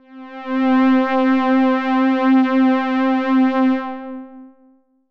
DX String C4.wav